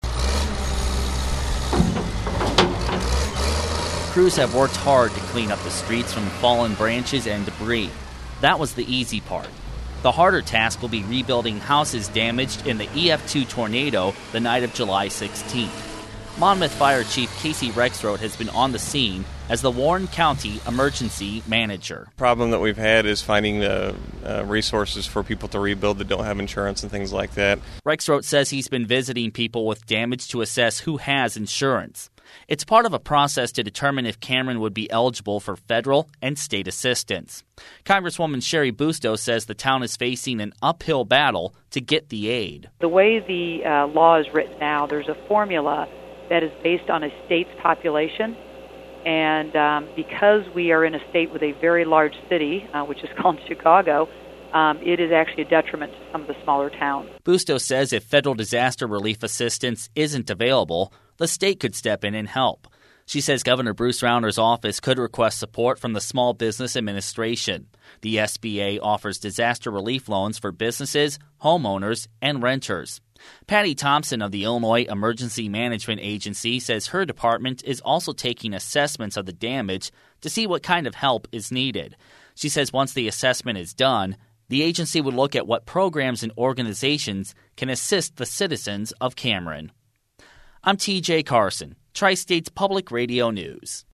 The radio story.